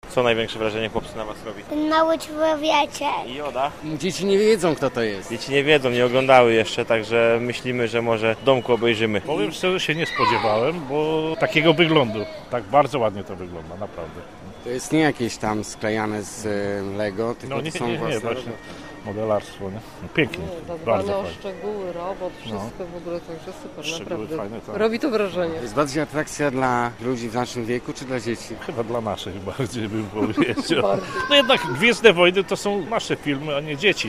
Wojny-Gwiezdne-sonda-Elblag.mp3